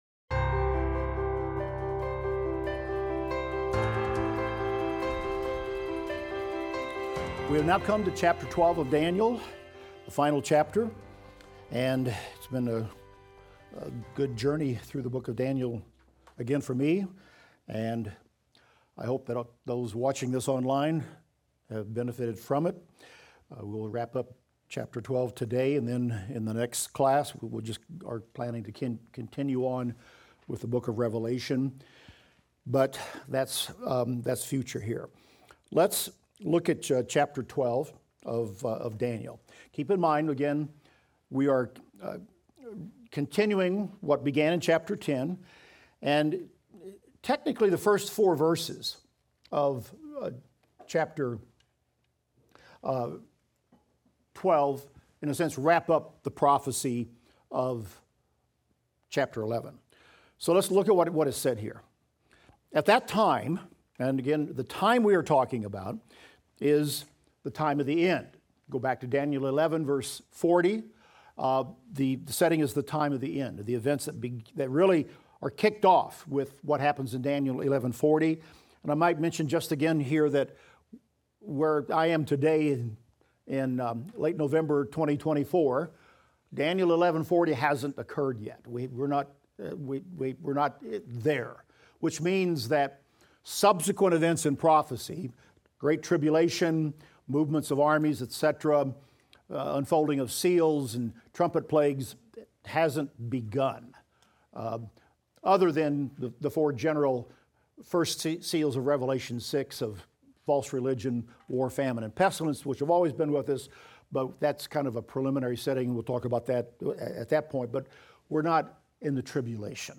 Daniel - Lecture 23 - audio_0.mp3